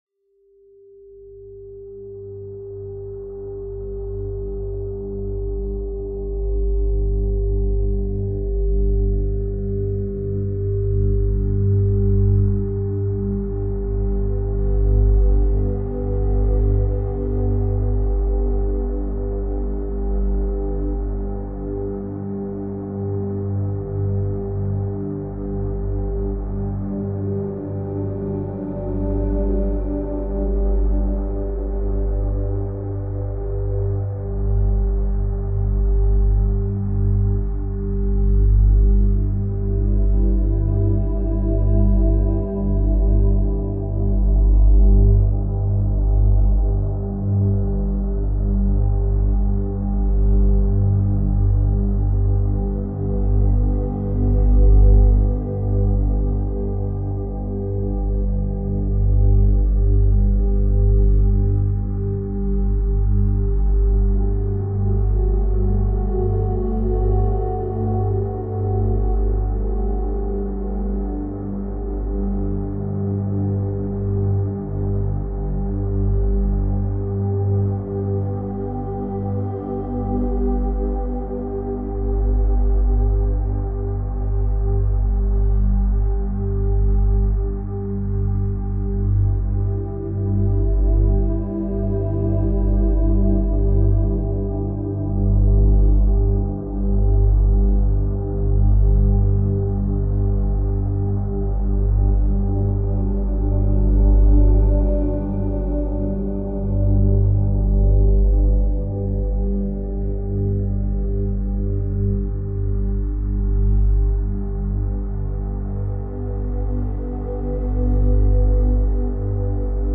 La fréquence 396 Hz détruit les blocages de l’inconscient
FRÉQUENCES VIBRATOIRES